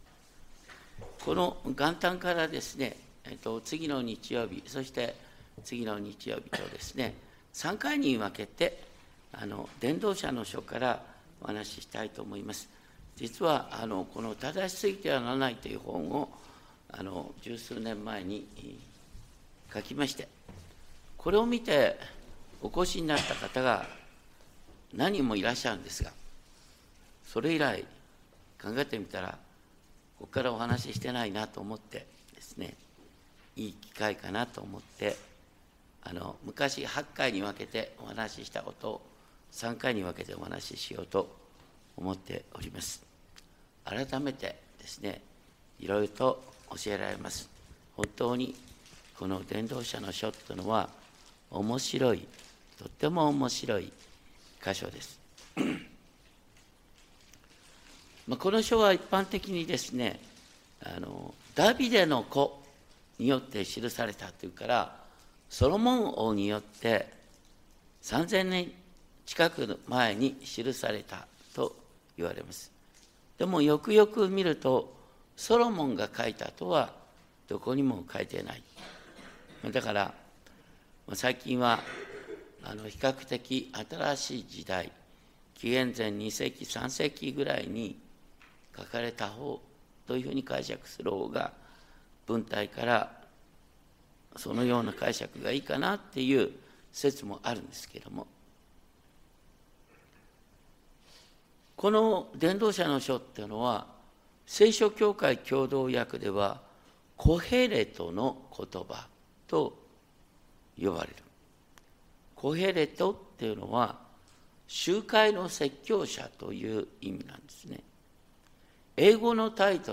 2025.1.1 礼拝メッセージ（伝道者の書1–3） この書は、｢エルサレムの王、ダビデの子、伝道者のことば」と最初に紹介されており、伝統的にダビデの子、ソロモン王によって今から三千年近く前に記されたと言われます。